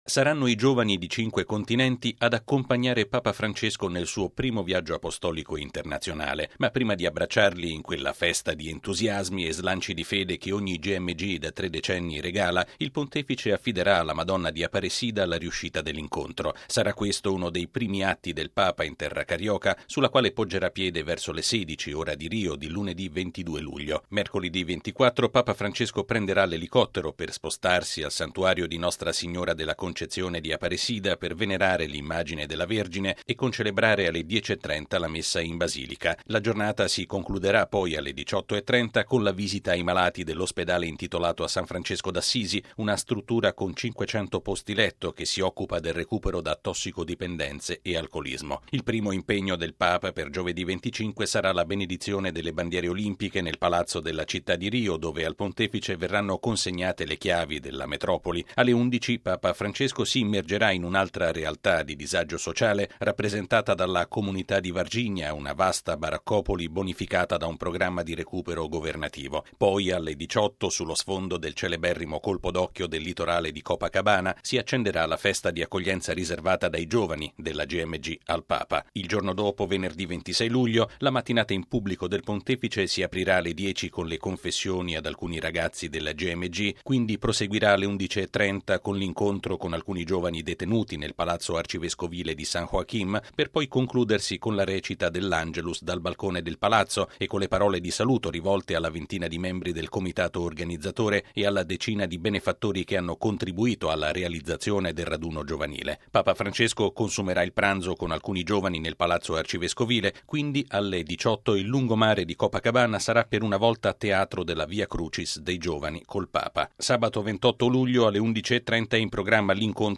◊   Papa Francesco sarà in Brasile dal 22 al 29 luglio per presiedere agli eventi della 28.ma Giornata mondiale della gioventù, in programma a Rio de Janeiro sul tema “Andate e fate discepoli tutti i popoli”. Nella metropoli carioca, il Pontefice avrà numerosi incontri istituzionali e altri a dimensione ecclesiale e all’insegna della solidarietà, ma anche un momento di incontro con la Chiesa locale ad Aparecida. Il servizio